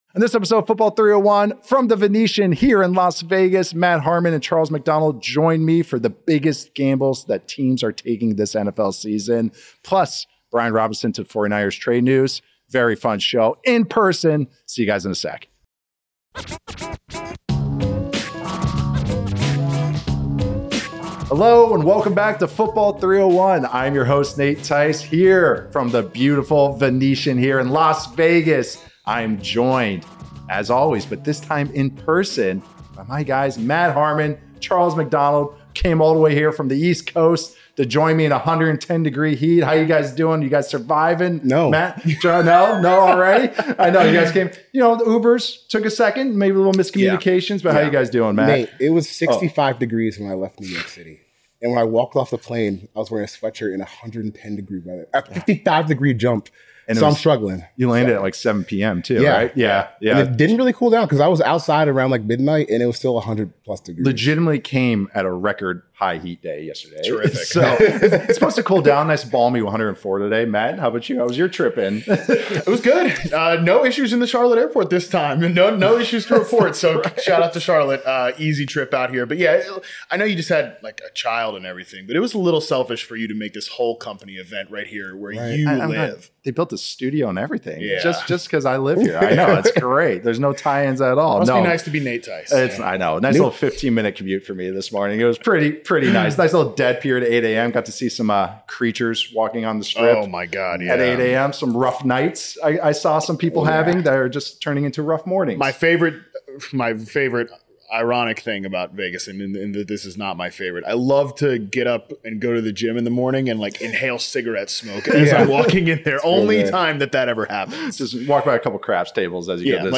Pro Football, Sports News, Nfl, American Football, Sports, News, Football